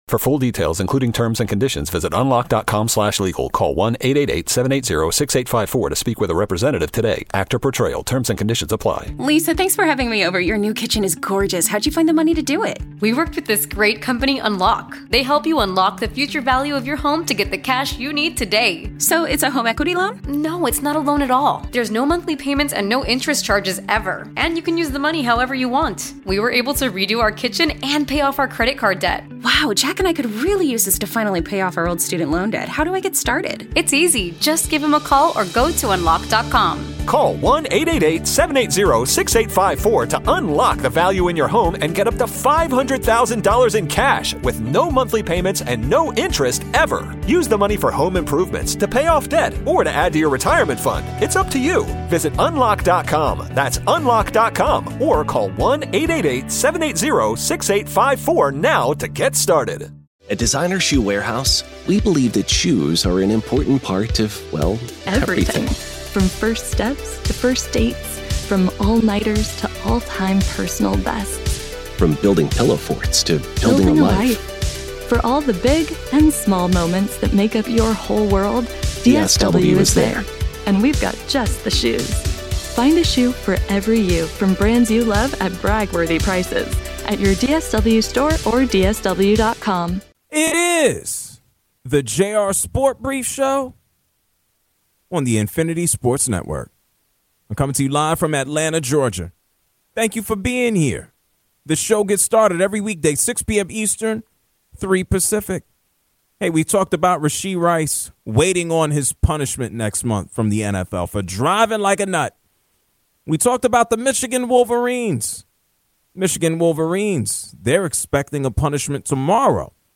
| A caller talks about NFL cheerleaders.